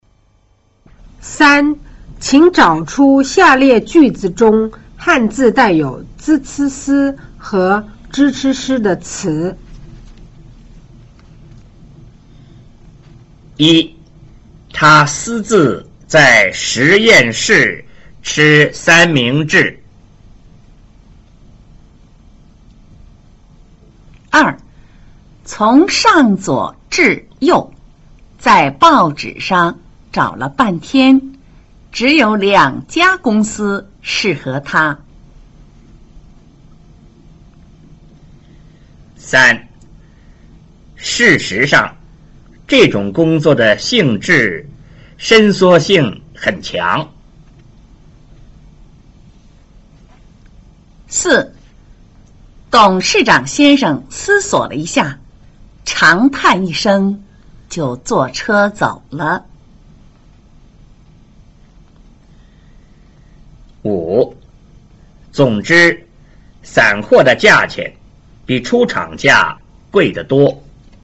3. 請找出下列句子中漢字帶有 z c s 和 zh ch sh 的詞﹕